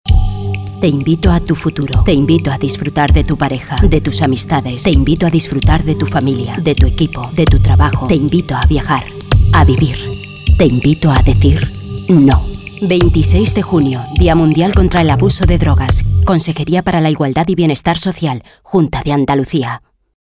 2. Emisión de cuñas de radio: -